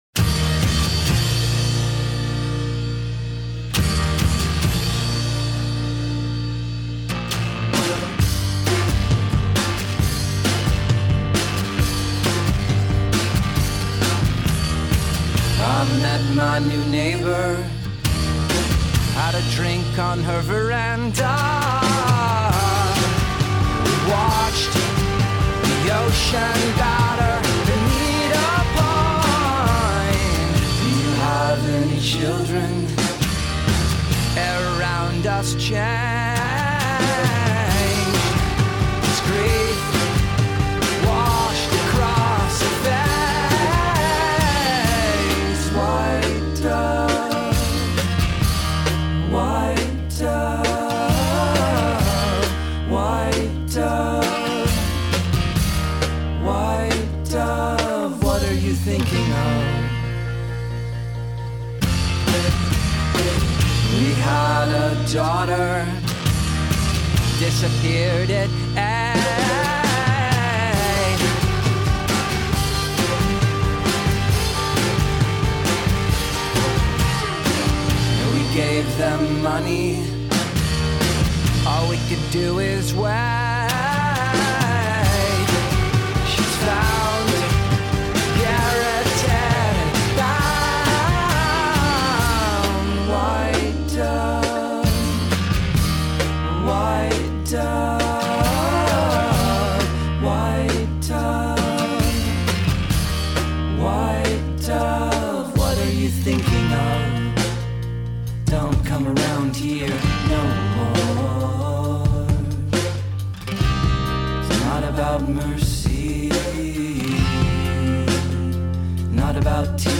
A comp of the Bay Area’s Indie Pop.
Indie-Pop.